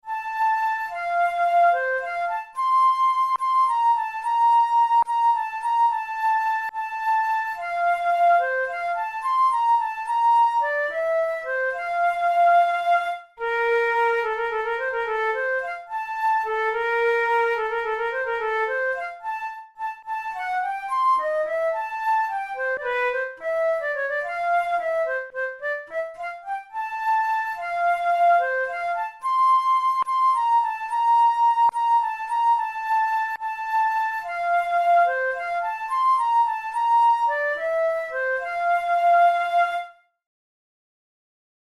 Categories: Etudes Written for Flute Difficulty: easy